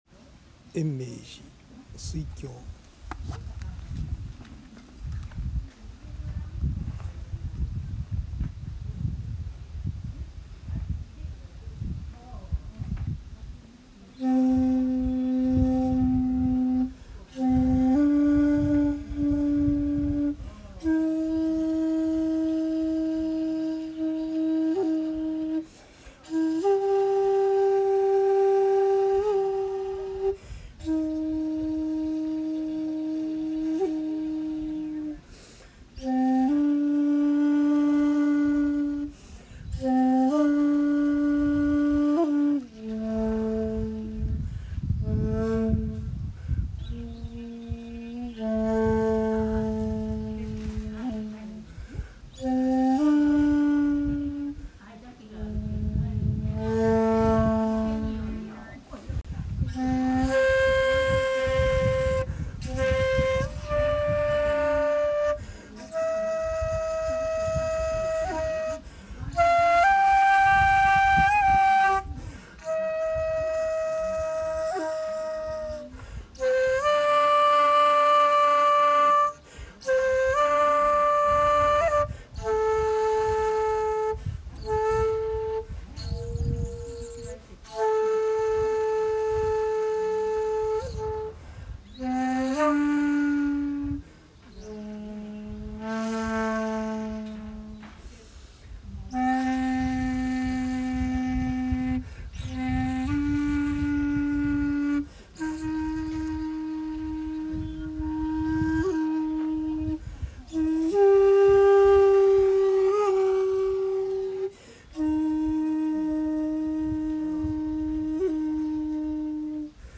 大きな霊園の中を通り抜け、さらに遍路道を西に行くと延命寺に到着15:43。
納経して、尺八の吹奏をしました。
◆（尺八音源：延命寺「水鏡」）